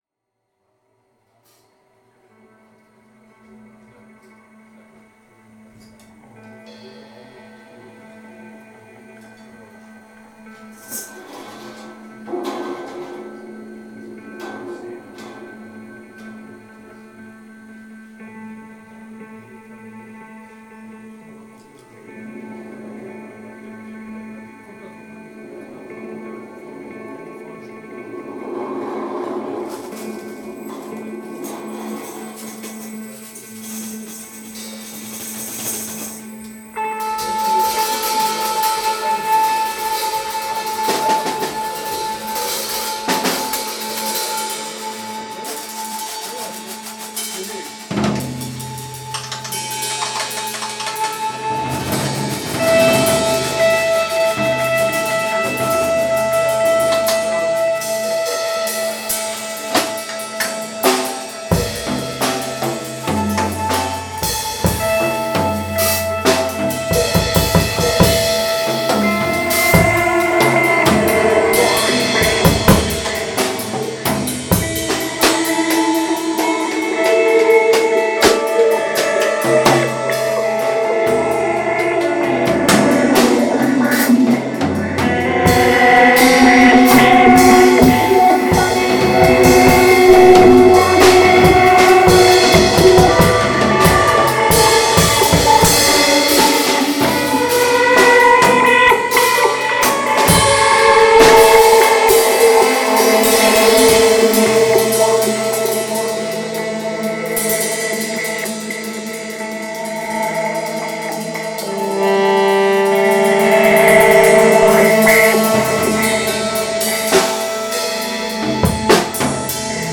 Saxophone/Bassklarinette/Percussion
E-Gitarren
Schlagzeug
Live aufgenommen bei der Soester Jazznacht 07.02.2026